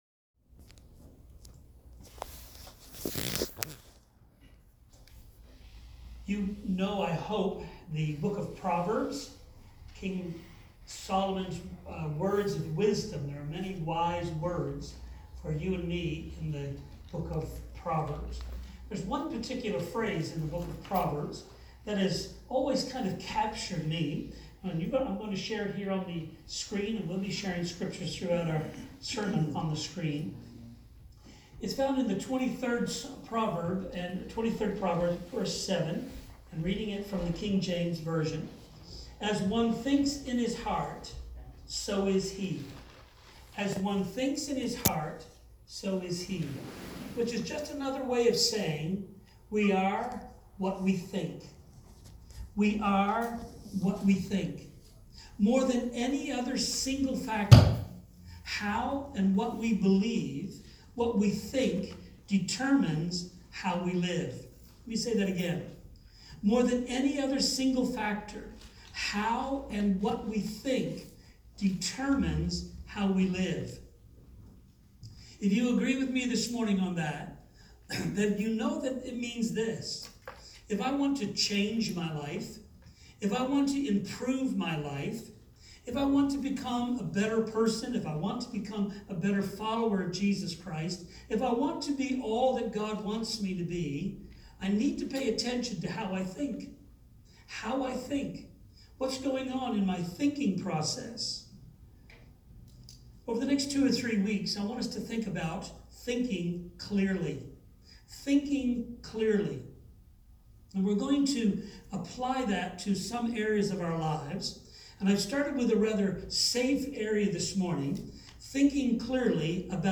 Sept 8 2019 Sermon